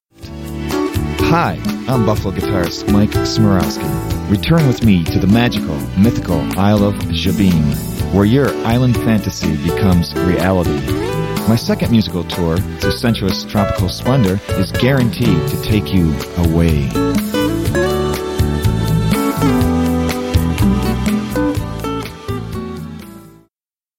Playing electric, classical and acoustic guitars